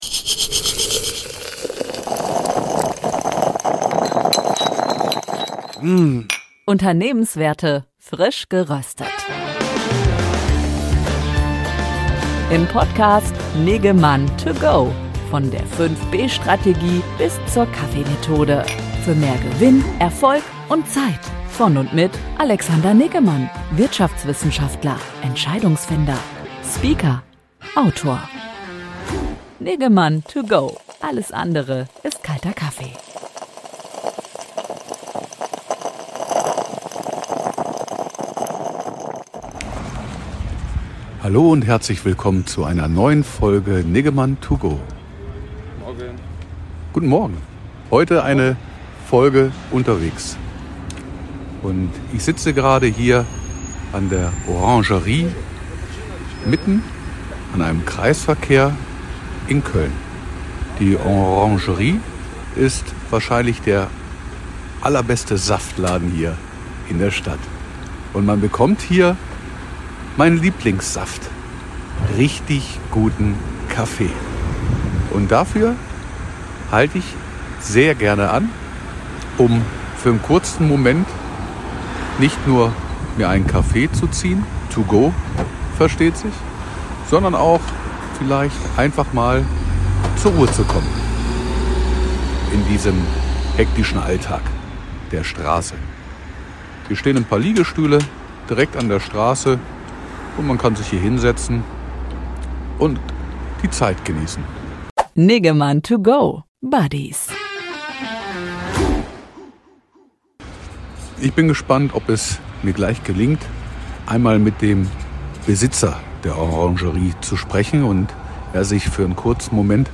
auf einen der Liegestühle vor der Orangerie.